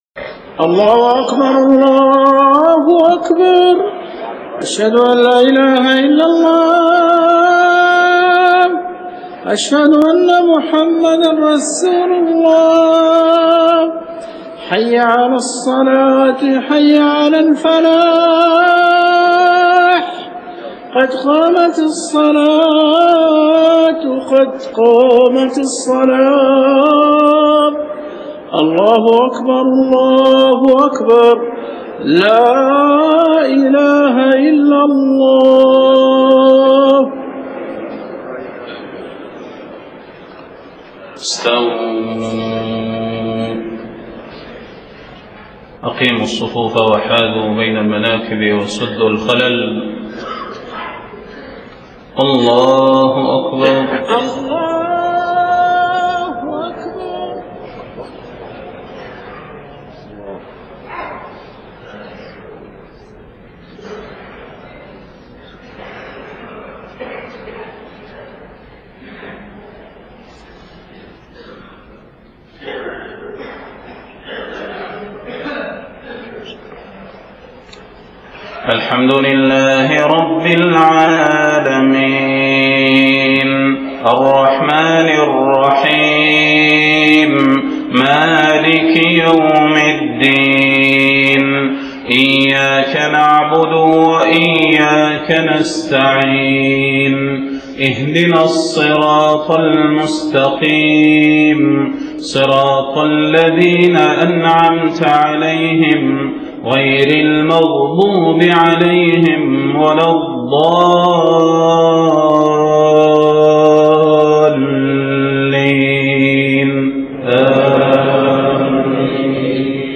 صلاة الفجر13 محرم 1430هـ من سورة المائدة 64-76 > 1430 🕌 > الفروض - تلاوات الحرمين